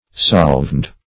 Search Result for " solvend" : The Collaborative International Dictionary of English v.0.48: Solvend \Sol"vend\ (s[o^]l"v[e^]nd), n. [L. solvendus to be loosened or dissolved, fr. solvere.